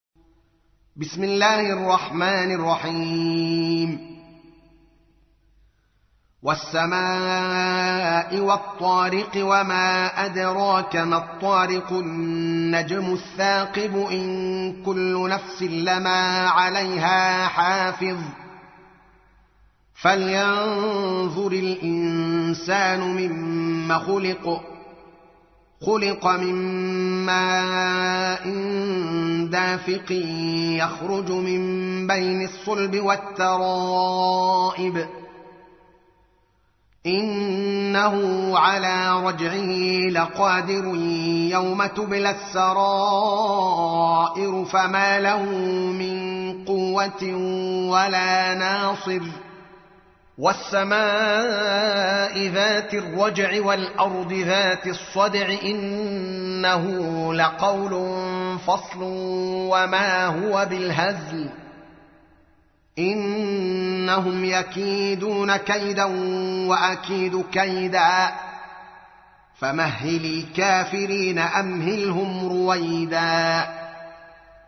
تحميل : 86. سورة الطارق / القارئ الدوكالي محمد العالم / القرآن الكريم / موقع يا حسين